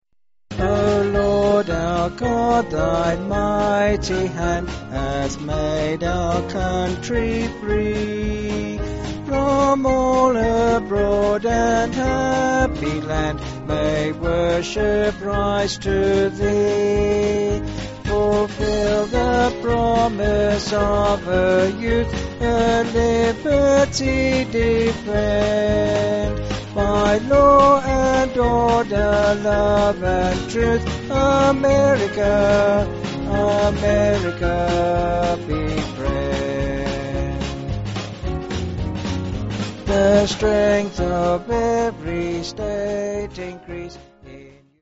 (BH)   4/Db-D
Vocals and Band